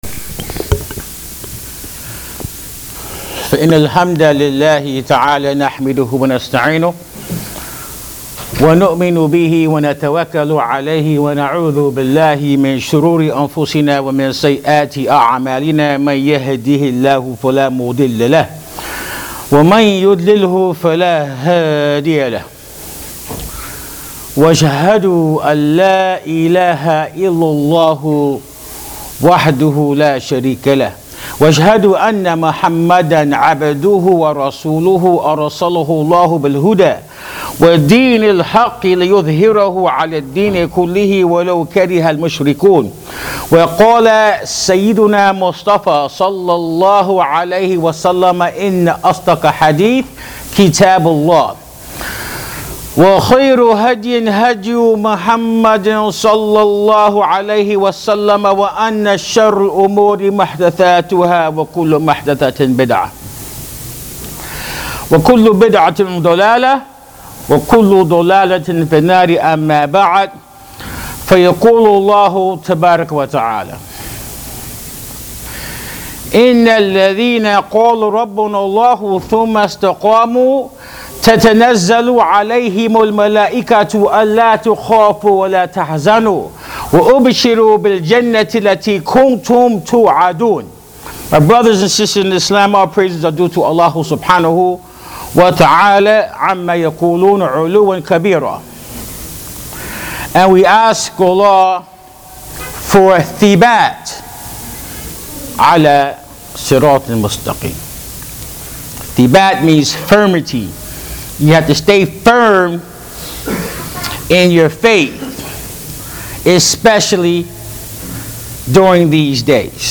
Living right is the topic of this khutbatul Jum’ah at Masjid Ibrahim Islamic Center in Sacramento Ca. Click on the link below to take a listen.